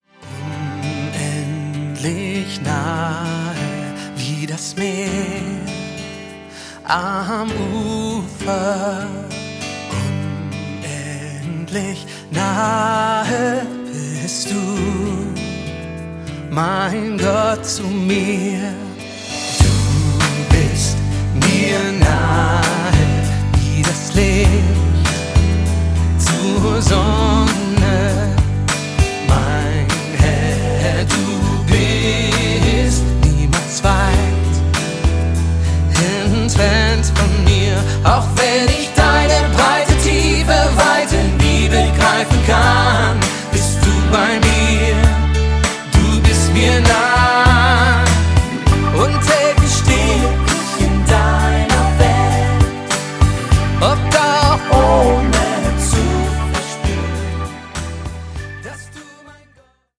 Einfach up-to-date-Grooves mit positiver Ausstrahlung!